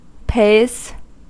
Ääntäminen
Ääntäminen UK : IPA : /peɪz/ US : IPA : /peɪz/ Haettu sana löytyi näillä lähdekielillä: englanti Käännöksiä ei löytynyt valitulle kohdekielelle. Pays on sanan pay monikko.